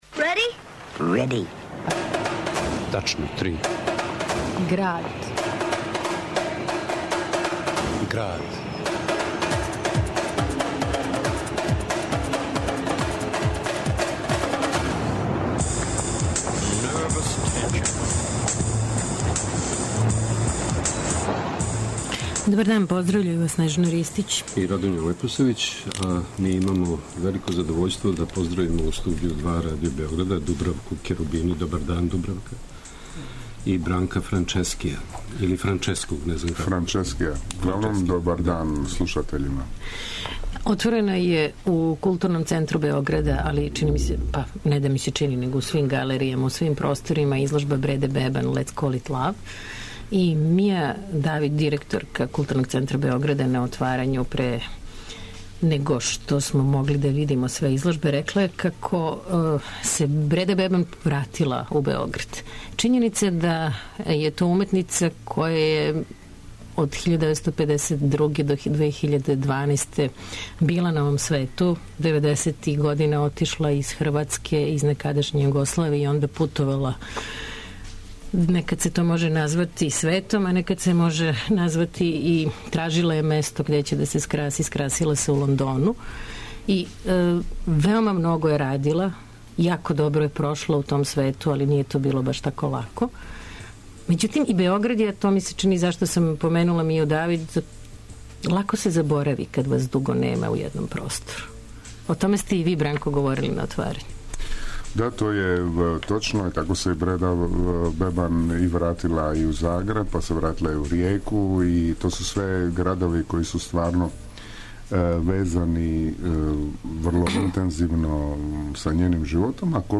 Делови интервјуа са Бредом Бебан, из 2001. године...